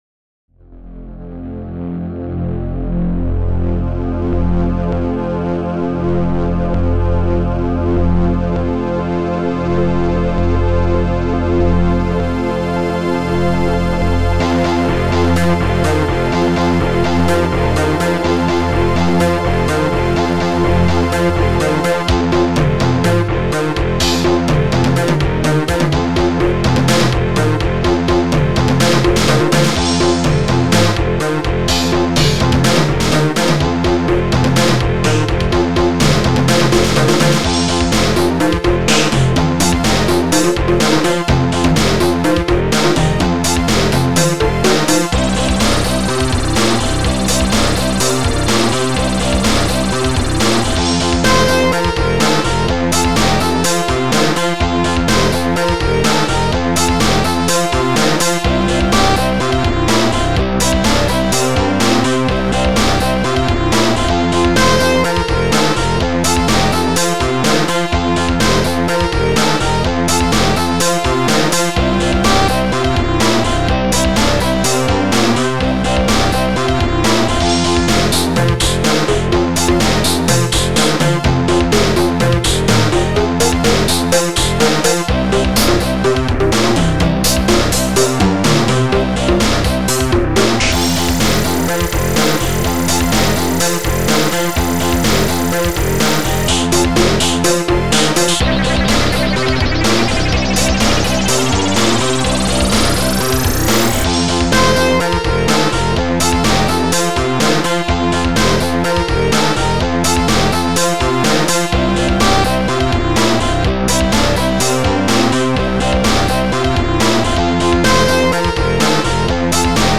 st-10:pulstrings
st-10:m1snare2
st-03:bassdrum10
st-04:crash3
st-10:m1conga
st-10:minimoog